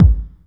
Track 08 - Kick OS.wav